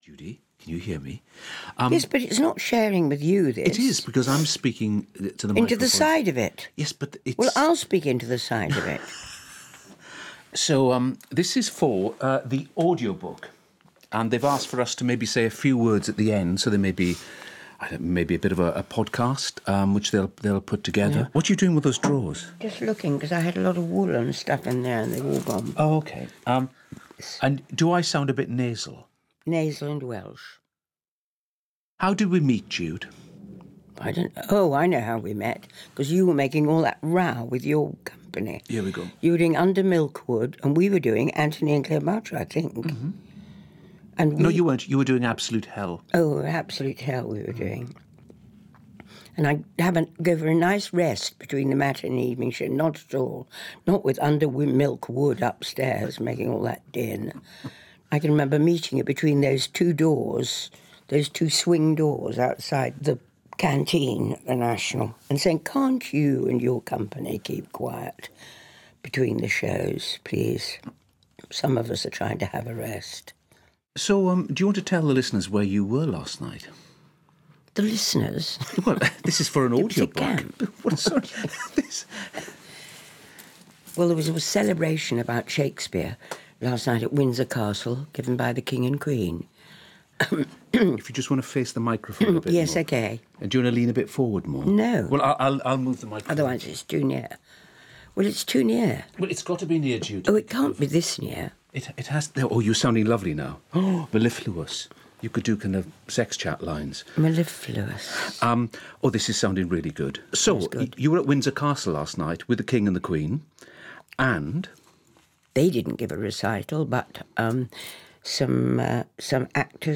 Audiobook
in Conversation